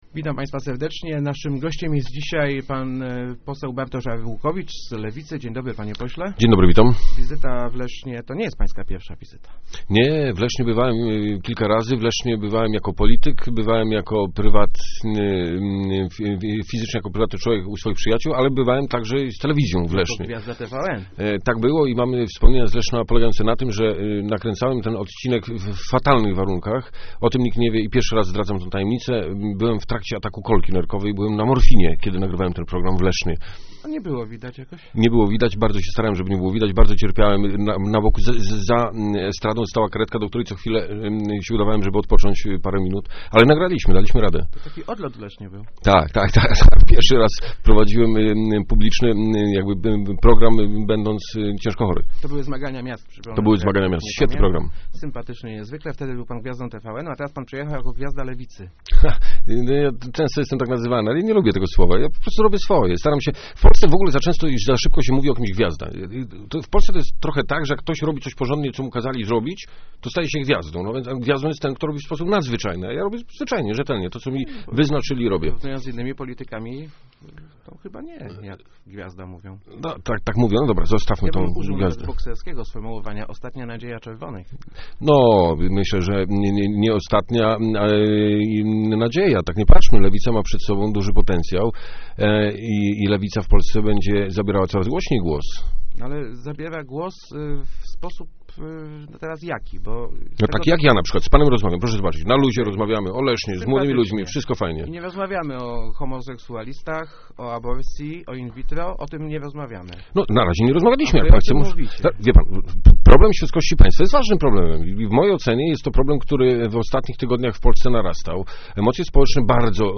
arukowicz80.jpgJestem przeciwnikiem radykalizowania czegokolwiek – mówił w Rozmowach Elki poseł SLD Bartosz Arłukowicz. Odpiera on argumenty o polaryzowaniu Lewicy przeciwko Kościołowi.